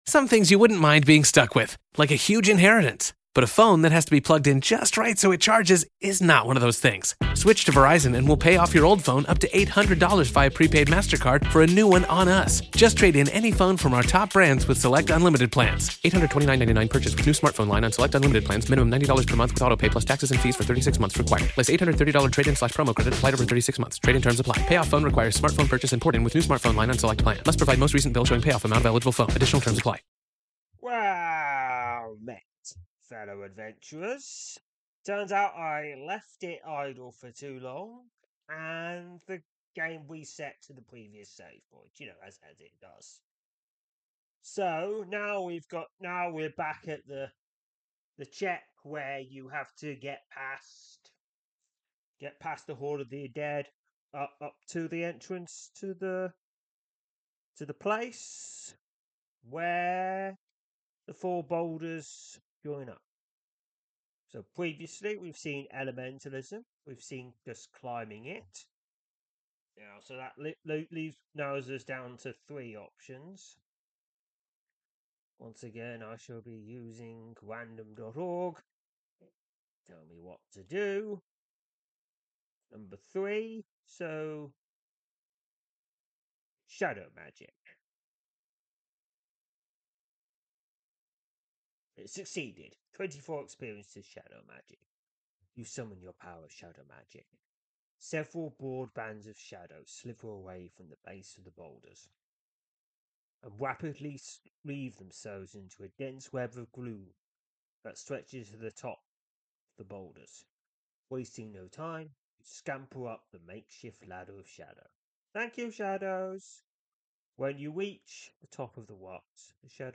I read out and play a selection of adventures from the the fantasy browser RPG Sryth.